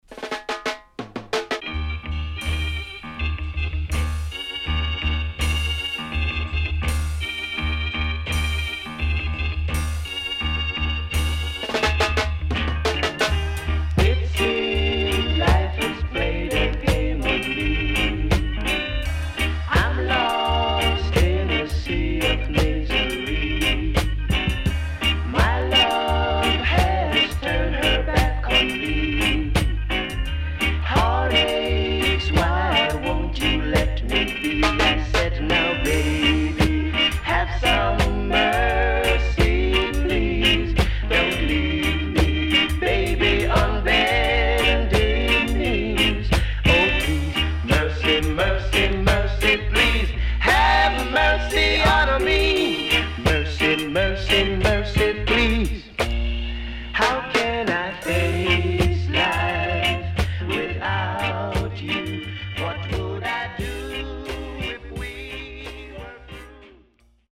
SIDE B:少しチリノイズ、プチノイズ入りますが良好です。